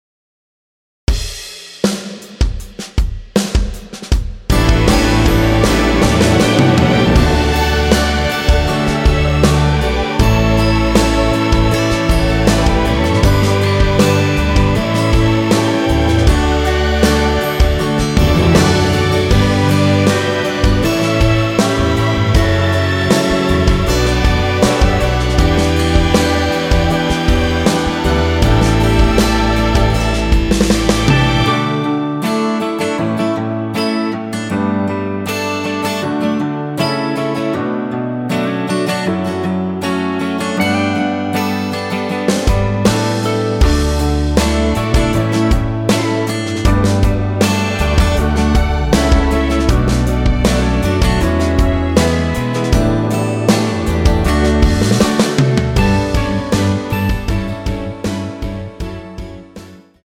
축가로 잘어울리는곡
여자키에서(+1) 더 올린 MR입니다.
앞부분30초, 뒷부분30초씩 편집해서 올려 드리고 있습니다.